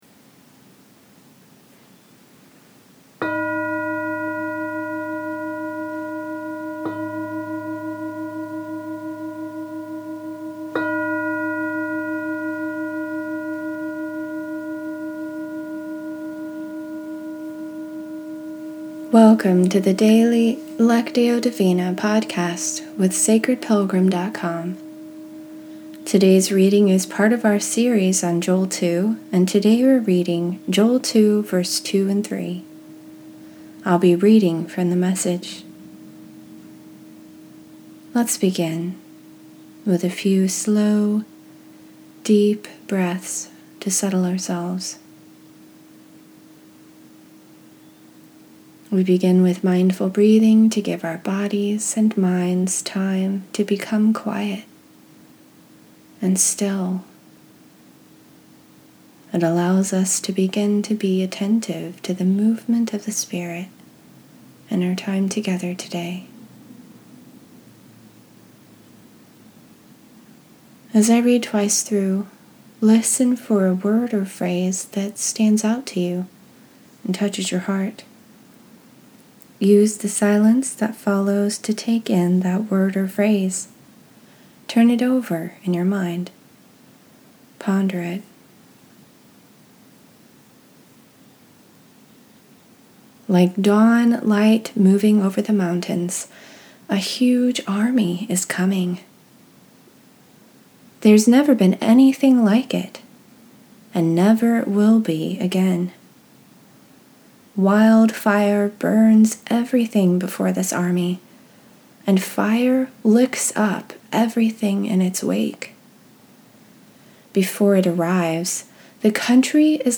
In this episode we’re reading Joel 2:2-3.